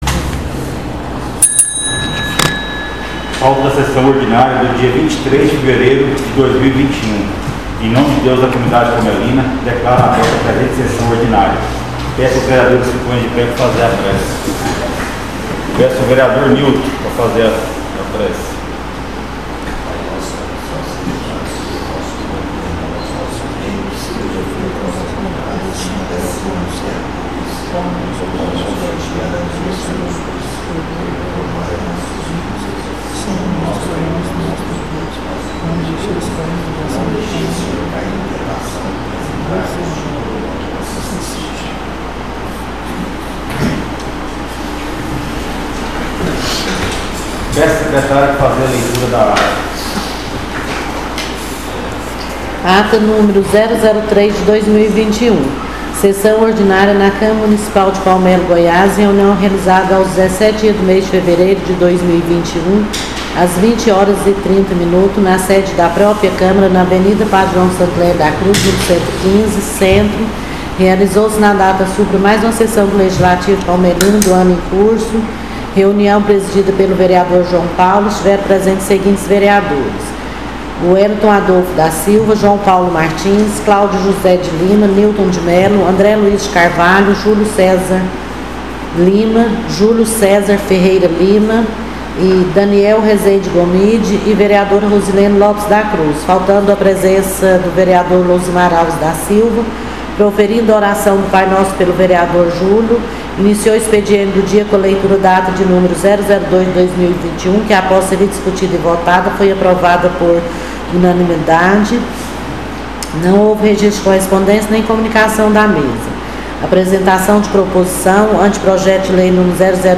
SESSÃO ORDINÁRIA DIA 23/03/2021